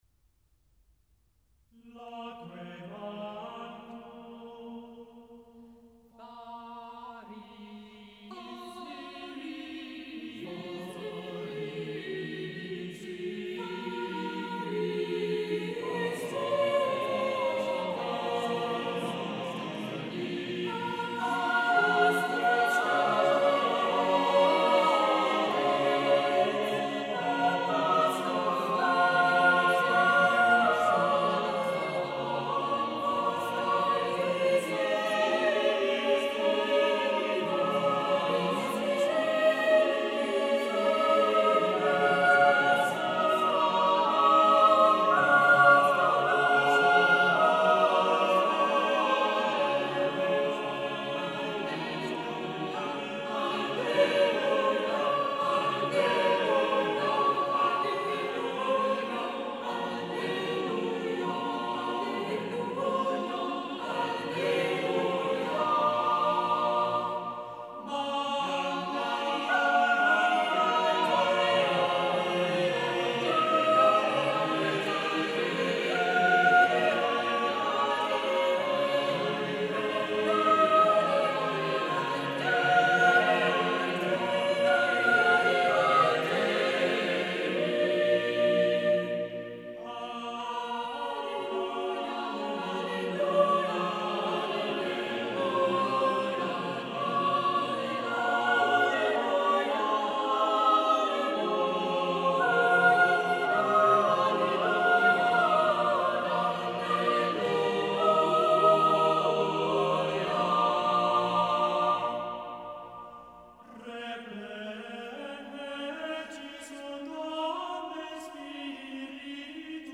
Listen to the Cambridge Singers perform "Loquebantur Variis Linguis" by Thomas Tallis.